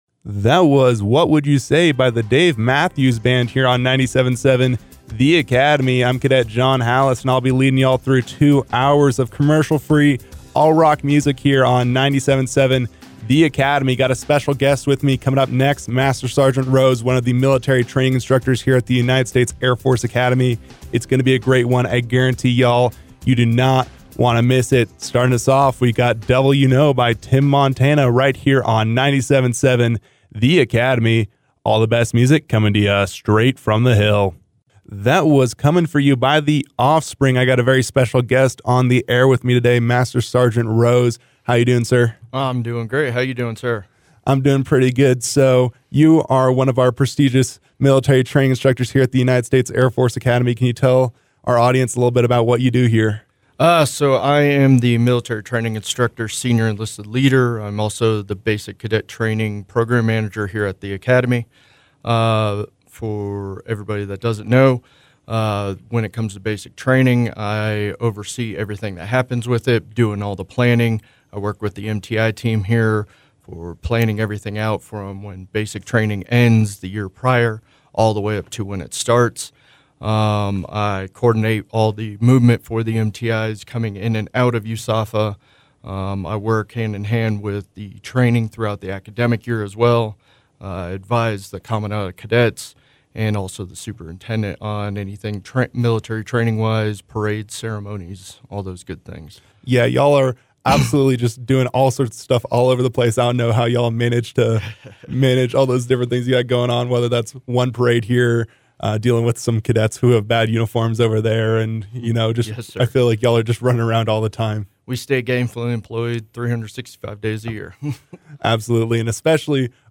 KAFA Cadet Interview